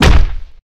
smack.ogg